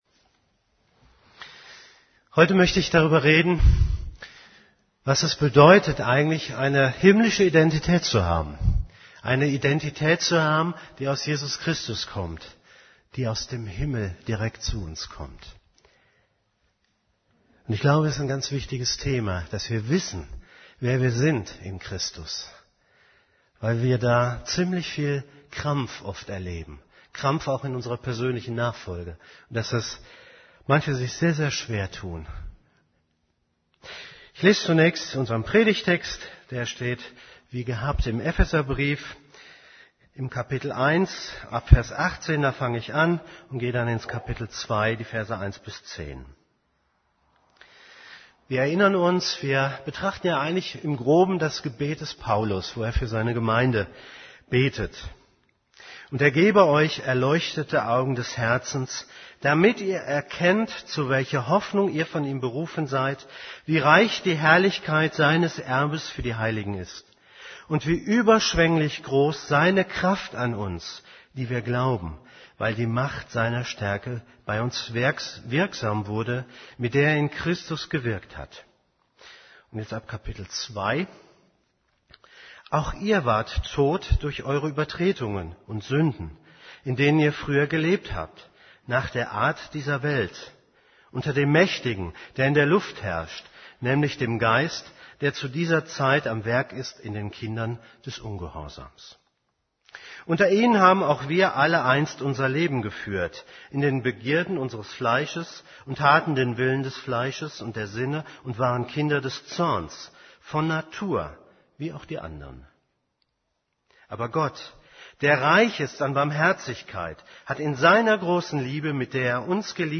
> Übersicht Predigten Unsere himmlische Identität Predigt vom 25.